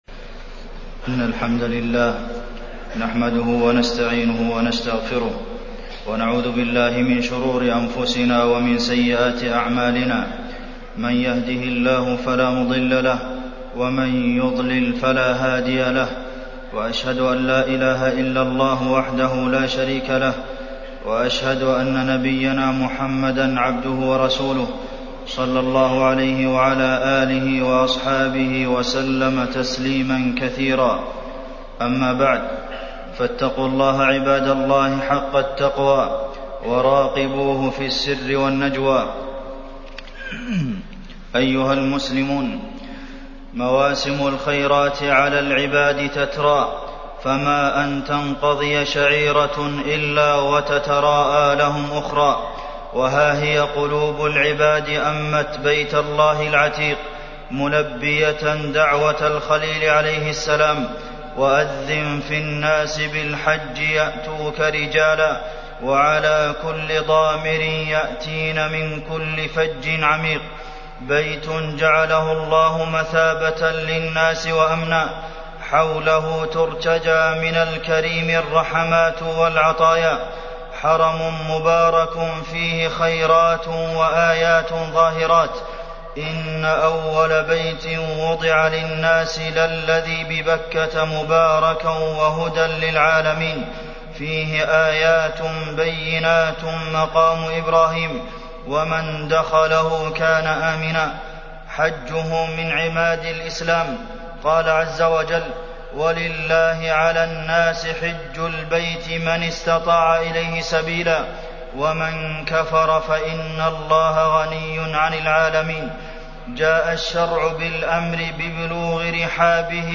تاريخ النشر ٢٤ ذو القعدة ١٤٢٧ هـ المكان: المسجد النبوي الشيخ: فضيلة الشيخ د. عبدالمحسن بن محمد القاسم فضيلة الشيخ د. عبدالمحسن بن محمد القاسم الحج